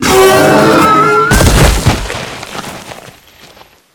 monsterdie.ogg